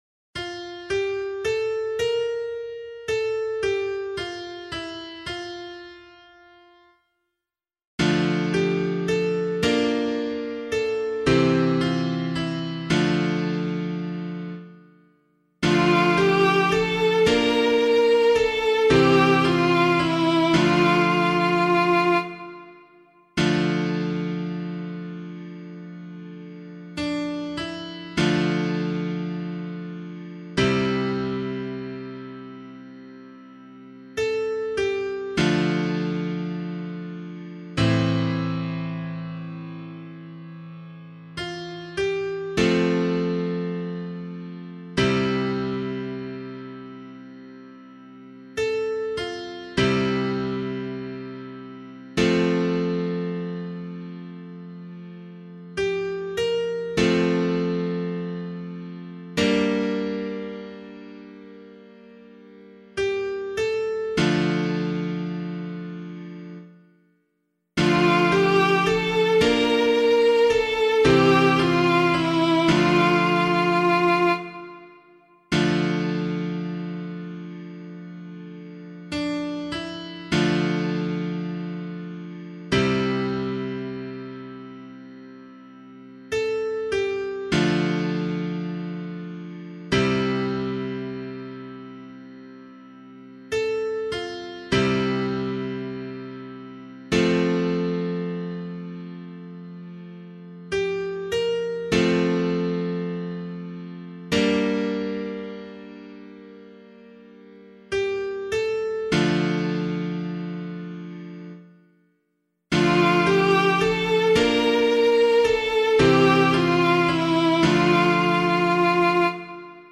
040 Ordinary Time 6 Psalm C [LiturgyShare 1 - Oz] - piano.mp3